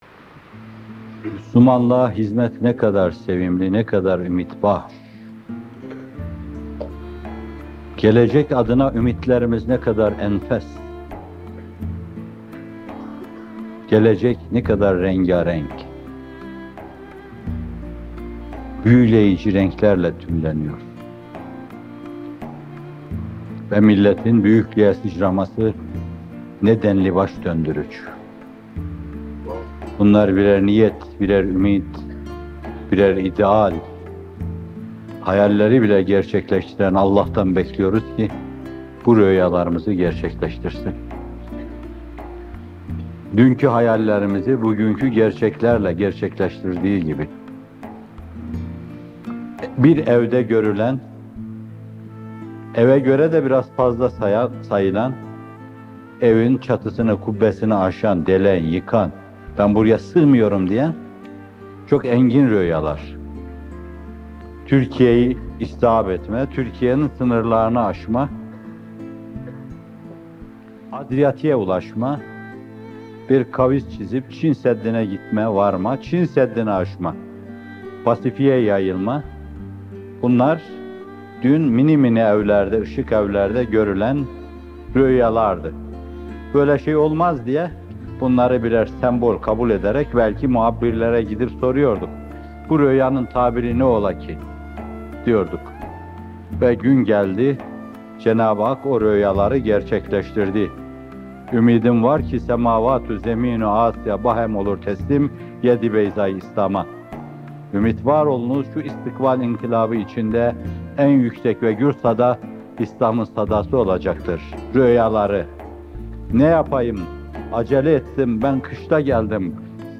Muhterem Fethullah Gülen Hocaefendi, Samanyolu Koleji öğrencileriyle bir arada! Muhterem Hocaefendi, bir niyet, bir ümit ve bir ideal olarak milletimize dair hayallerini dile getiriyor.
Muhterem Fethullah Gülen Hocaefendi, Nurlu Geleceğin Rüyası “Akyol” şiirini seslendiriyor.